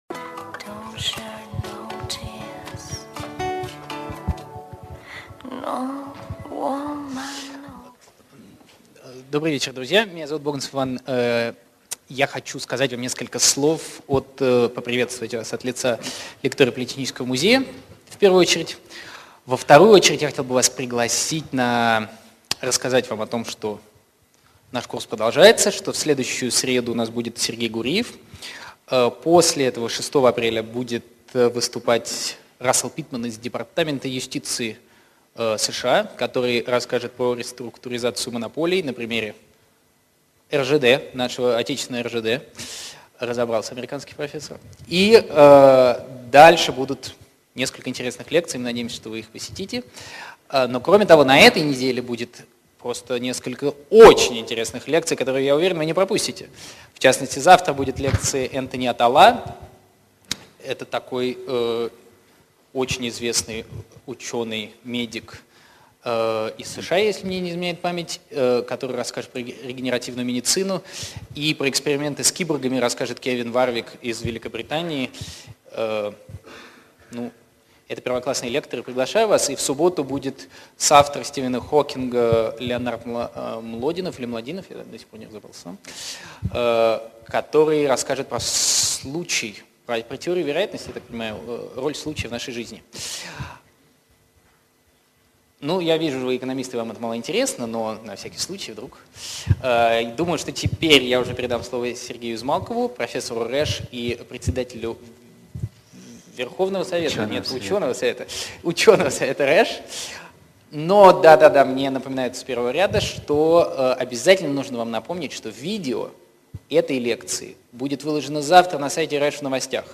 Аудиокнига Лекция №05 «Экономический дизайн» | Библиотека аудиокниг